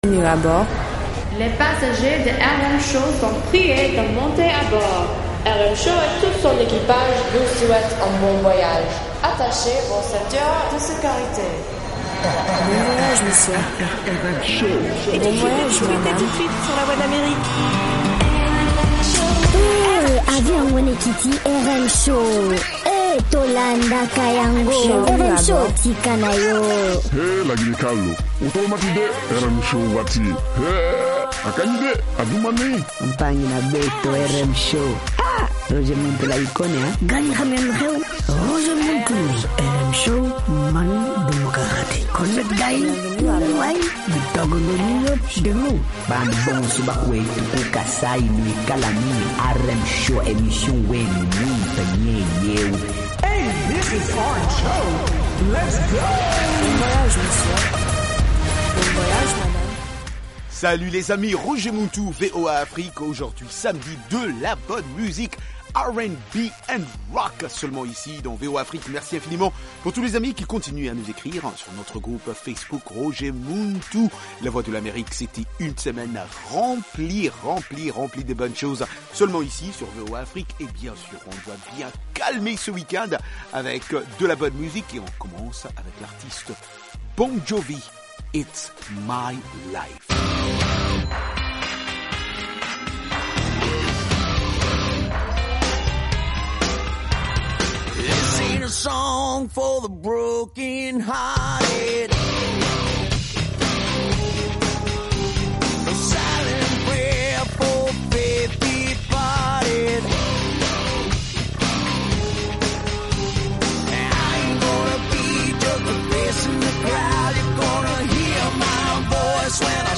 R&B et Rock